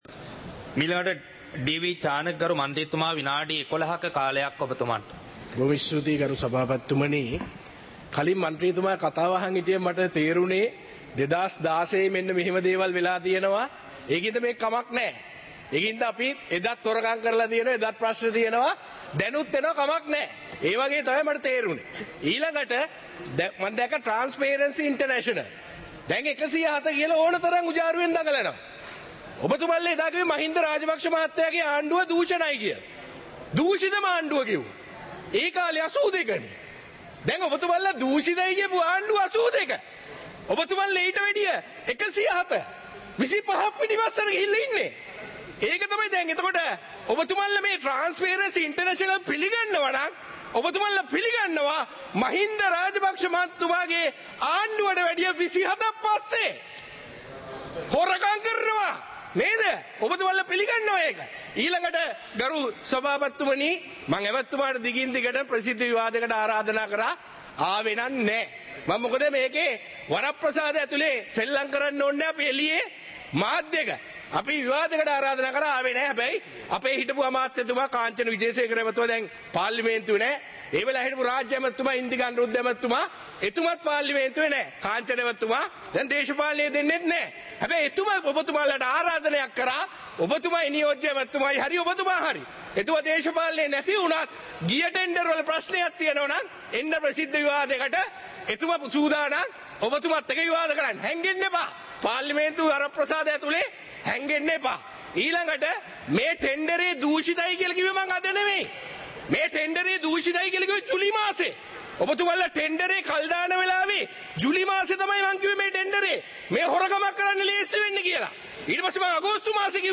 සභාවේ වැඩ කටයුතු (2026-02-20)
පාර්ලිමේන්තුව සජීවීව - පටිගත කළ